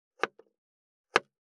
511,切る,包丁,厨房,台所,野菜切る,咀嚼音,ナイフ,調理音,まな板の上,料理,
効果音厨房/台所/レストラン/kitchen食器食材